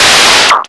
RADIOFX  9-R.wav